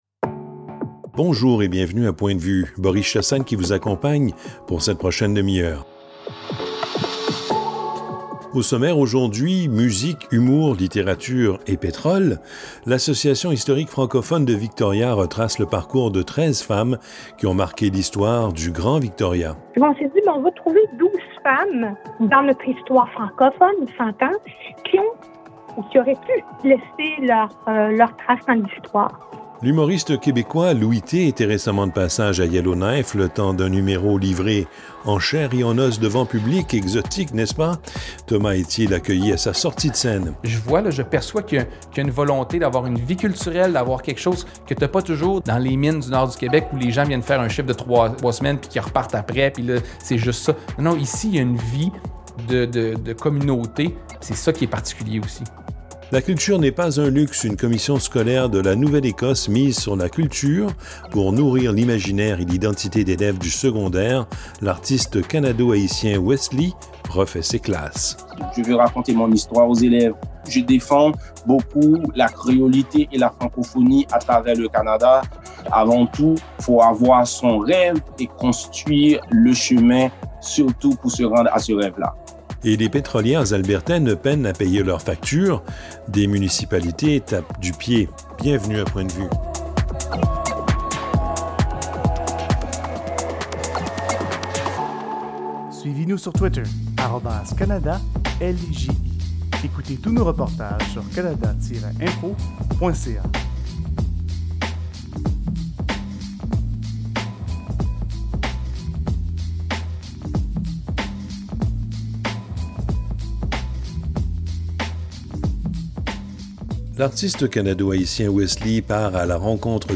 Une production du Fonds canadien de la radio communautaire.
Points de vue, saison 1, épisode 8 Points de vue en ondes sur 35 radio canadiennes.